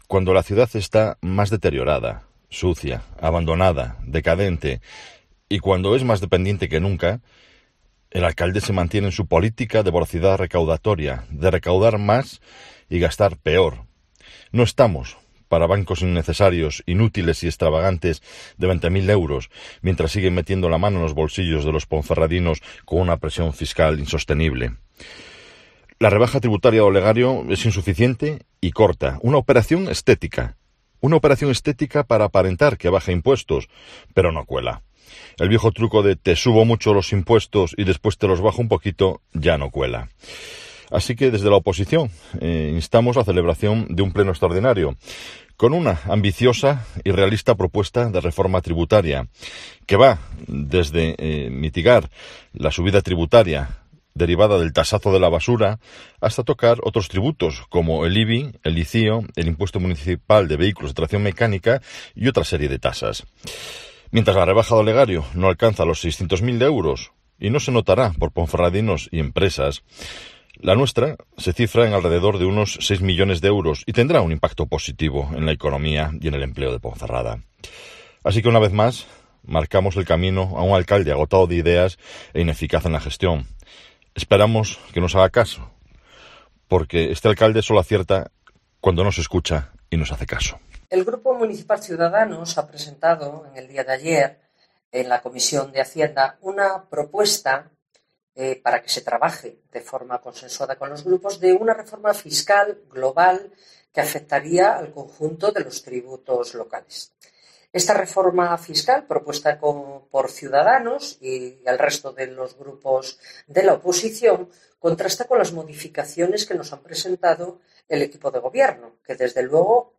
Escucha aqui al portavoz del PP, Marco Morala y a la concejala de Ciudadanos Teresa Magaz